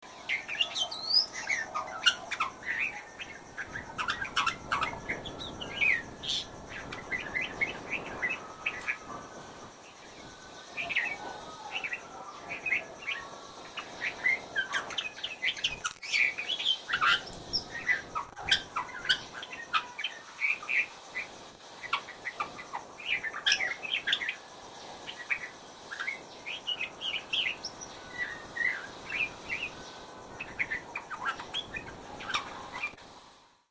Red-vented Bulbul – courtship song, sung softly
11-Red-vented-Bulbul_courtship-song.mp3